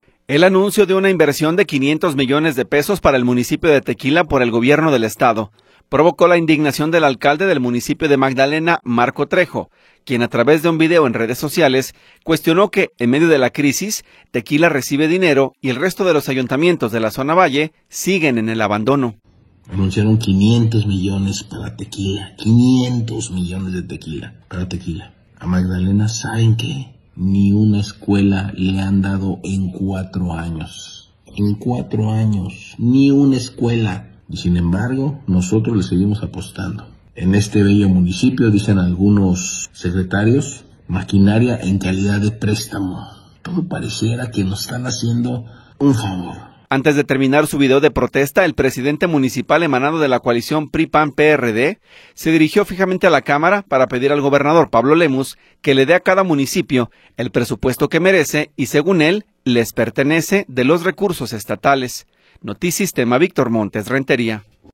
El anuncio de una inversión de de 500 millones de pesos para el municipio de Tequila por el Gobierno del Estado provocó la indignación del alcalde del municipio de Magdalena, Marco Trejo, quien a través de un video, en redes sociales, cuestionó que en medio de la crisis Tequila recibe dinero y el resto de ayuntamientos en la zona Valle siguen en el abandono.